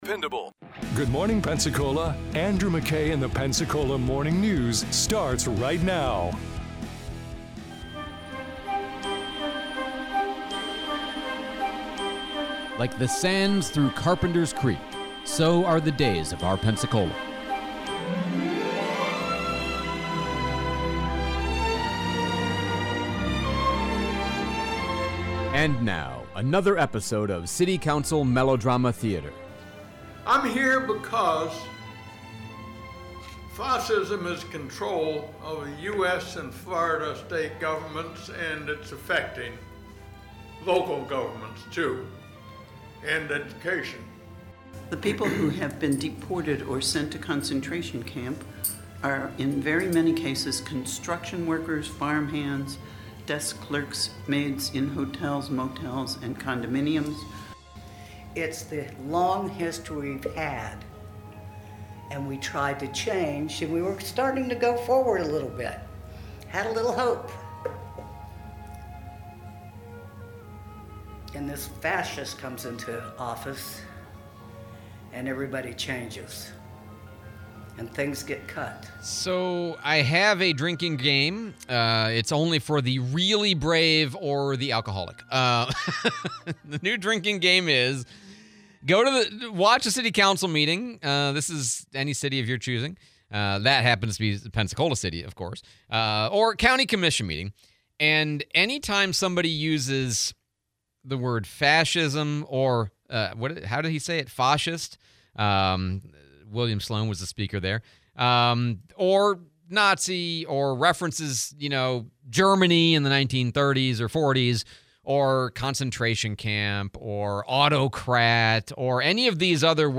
Public discourse, interview with Sheriff Chip Simmons